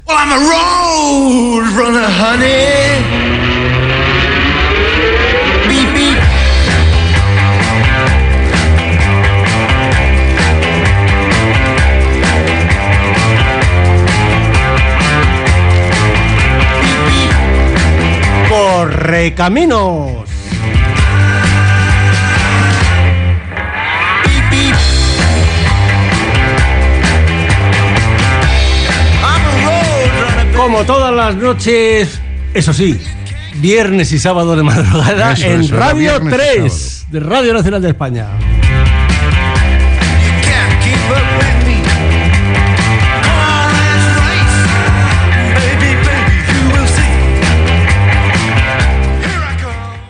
Recreació de la presentació del programa
Recreació feta per Fernando Argenta el dia 1 de juliol del 2009 al programa "30 años de Radio 3"